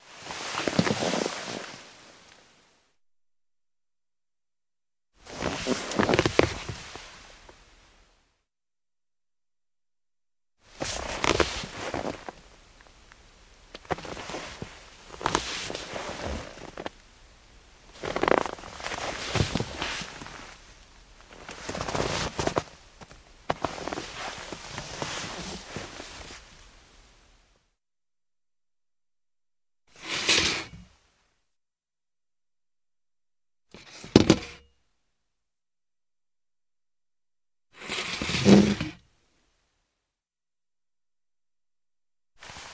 Kitchen Chairs Shuffling